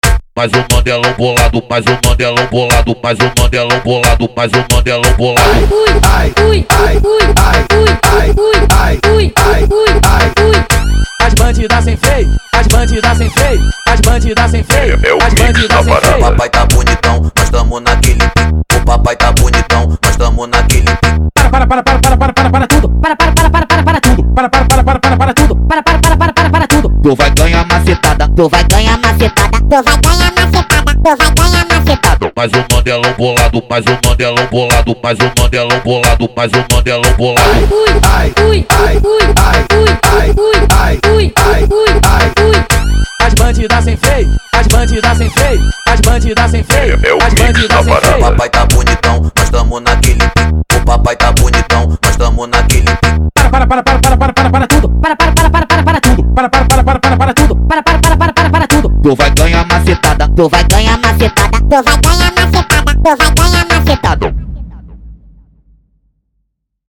Tecno Melody